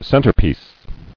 [cen·ter·piece]